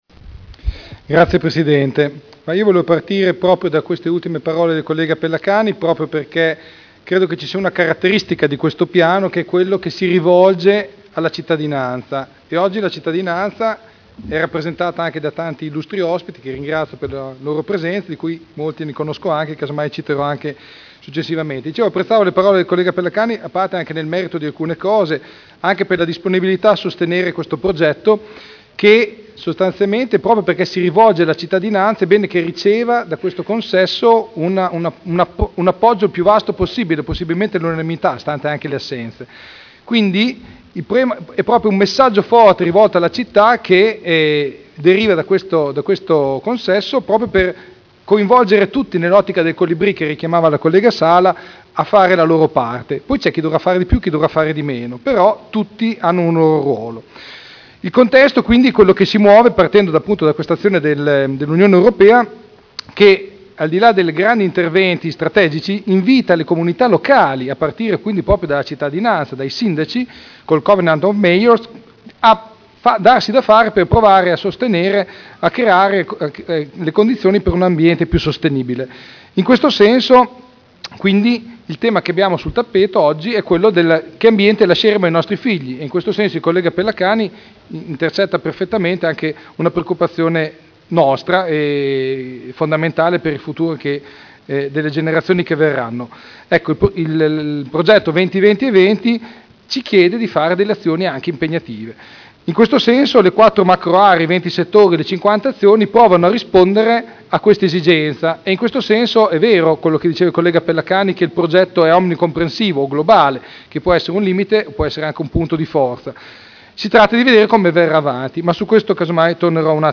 Seduta del 18/07/2011. Dibattito sul Piano d’Azione per l’Energia Sostenibile (SEAP)